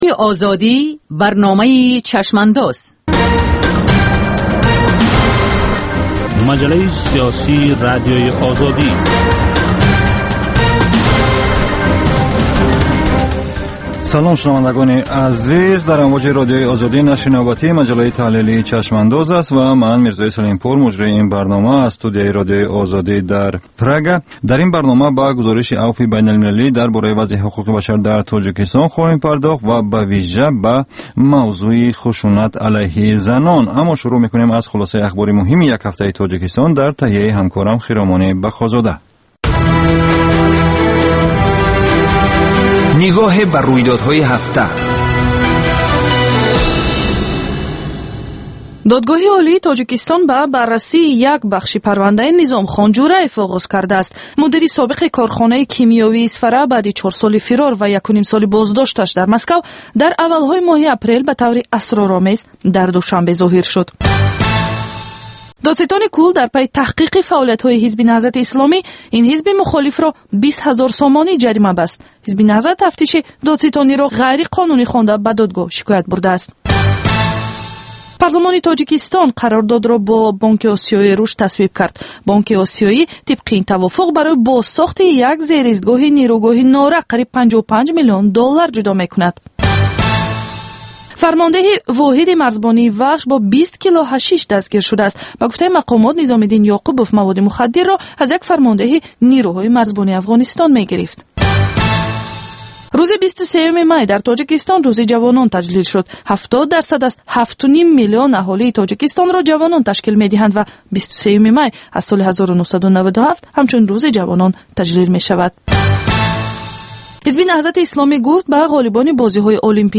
Ҷусторе дар рӯйдодҳои сиёсии ҷаҳон, минтақа ва Тоҷикистон дар як ҳафтаи гузашта. Мусоҳиба бо таҳлилгарони умури сиёсӣ.